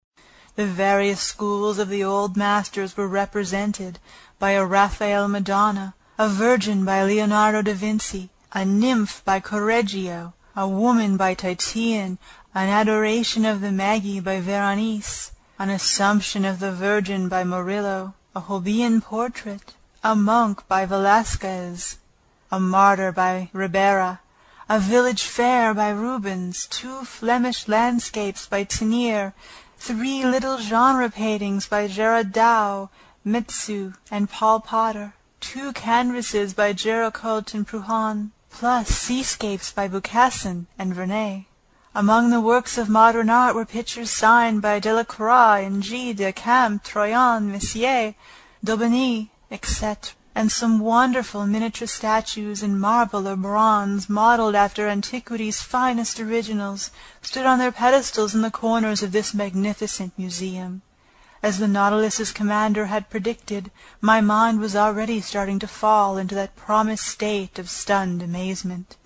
英语听书《海底两万里》第157期 第11章 诺第留斯号(8) 听力文件下载—在线英语听力室